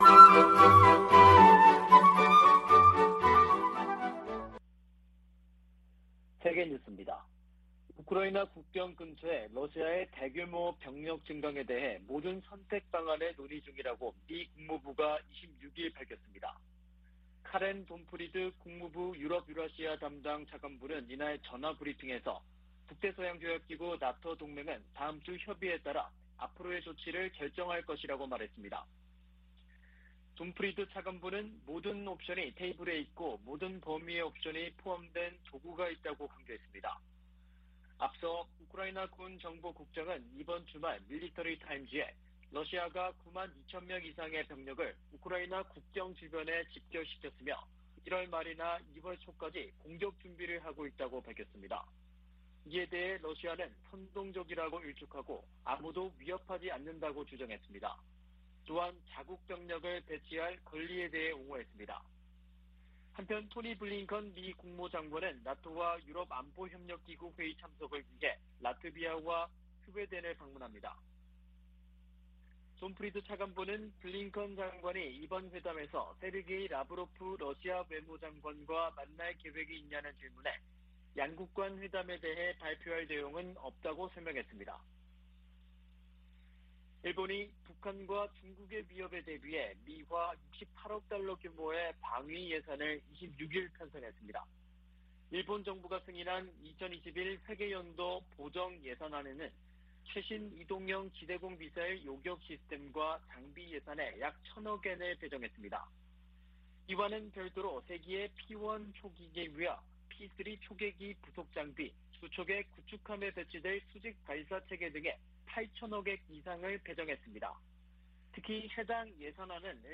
VOA 한국어 아침 뉴스 프로그램 '워싱턴 뉴스 광장' 2021년 11월 27일 방송입니다. 미 국무부는 북한의 계속된 핵 활동을 규탄하면서, 북한과의 비핵화 대화를 추구하고 있다고 밝혔습니다. 미국 상무부가 북한 유령회사에 미국과 다른 국가의 기술을 판매한 중국 기업 등, 국가안보에 위협이 되는 해외 기업들을 수출 규제 대상으로 지정했습니다. 북한 국영 고려항공이 또다시 유럽연합 회원국 내 운항이 엄격히 제한되는 항공사로 지정됐습니다.